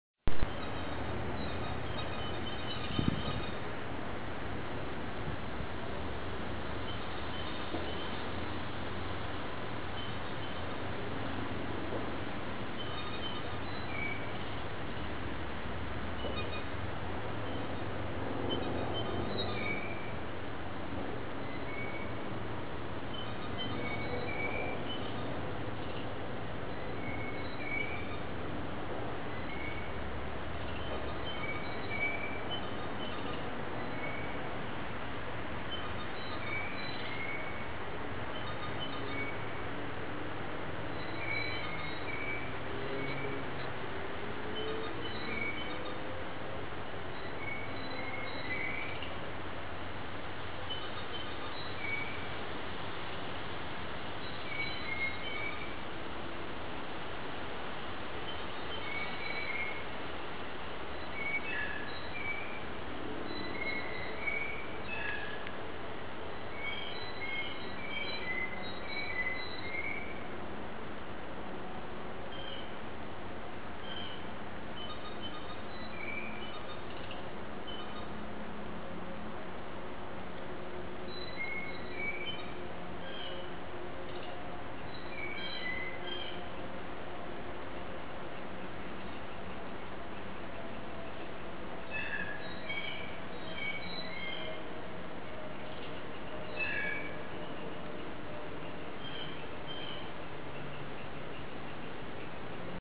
At the end of the orange trail, I came upon a band of blue jays. I grabbed my voice recorder and recorded some interesting sounds. Not only did they call out, but several blue jays made “clicking” sounds. If you turn the volume way up, you can hear it…sorry about the traffic noise…I have a cheap recorder and there is a street near by.
blue_jay_family_sound_bite.mp3